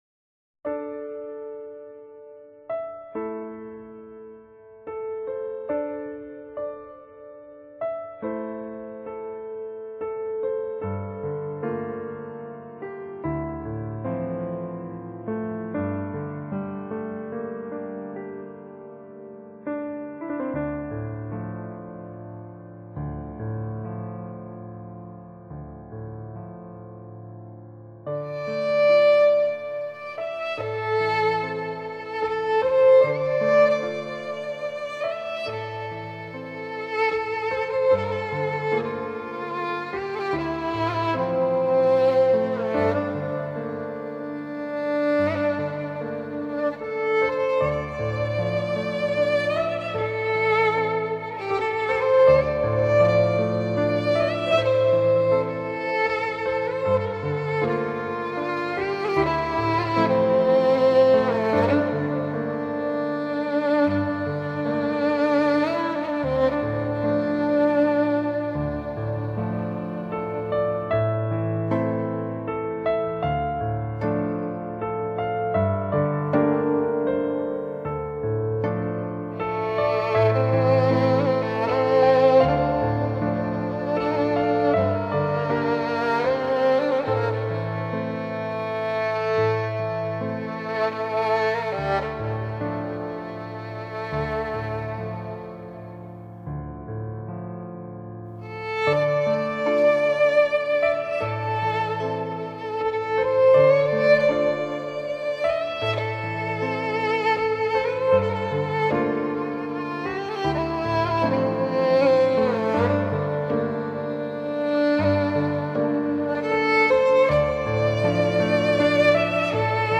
Genre: New Age, Easy Listening, Neo Classical